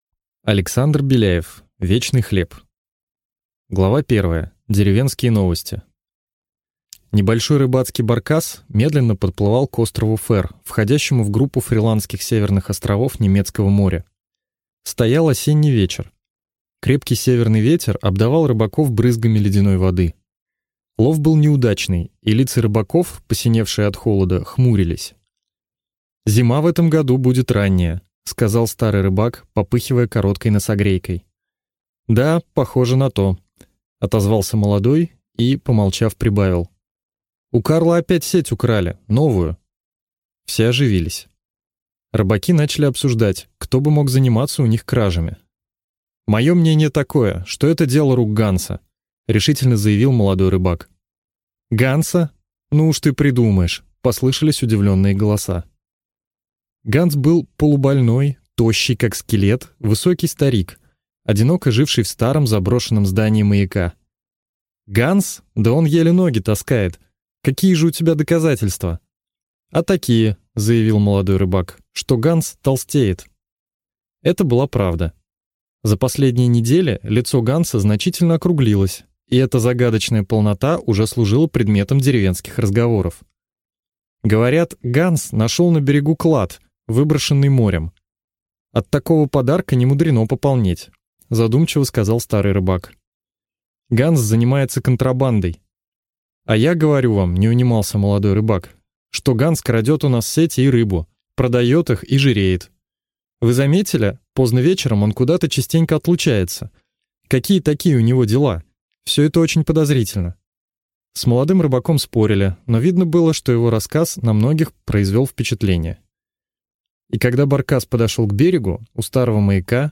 Аудиокнига Вечный хлеб | Библиотека аудиокниг